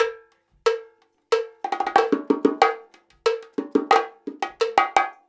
90 BONGO 1.wav